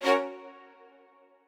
strings11_5.ogg